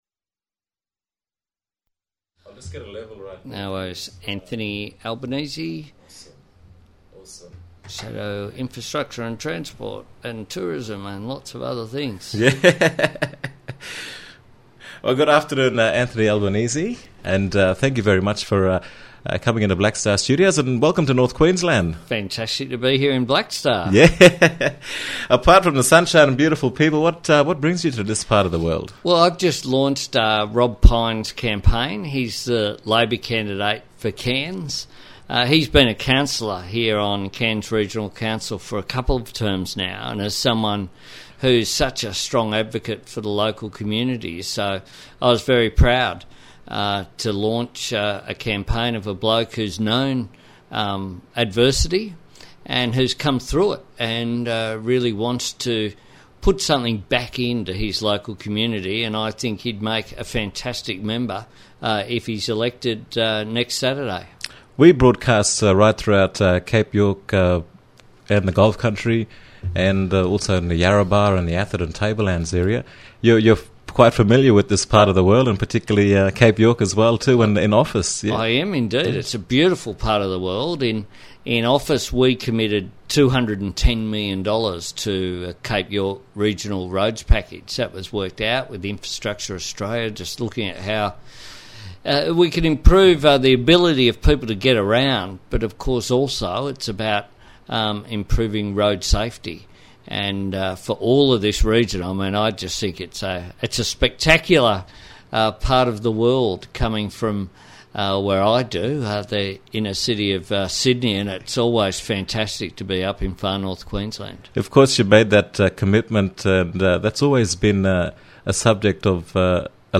You can listen to the entire interview with Anthony Albanese here.
interview-anthony-albanese.mp3